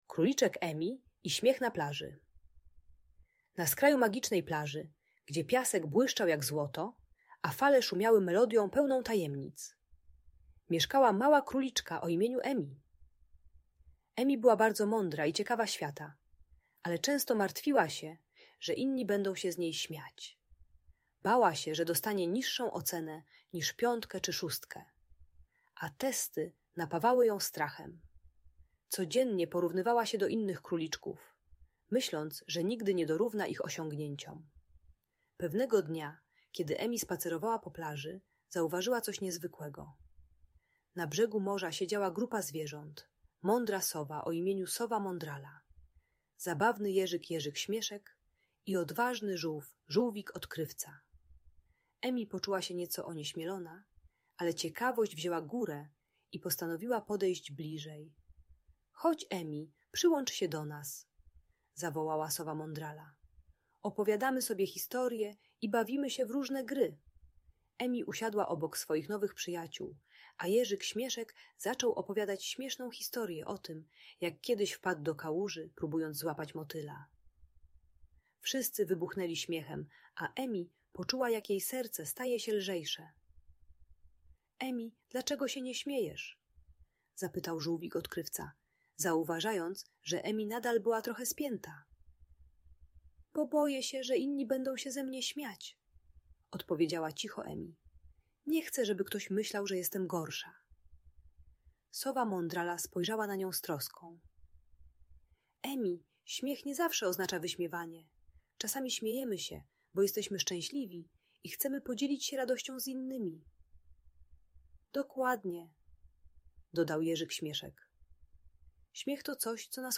Emi i Śmiech na Plaży - Lęk wycofanie | Audiobajka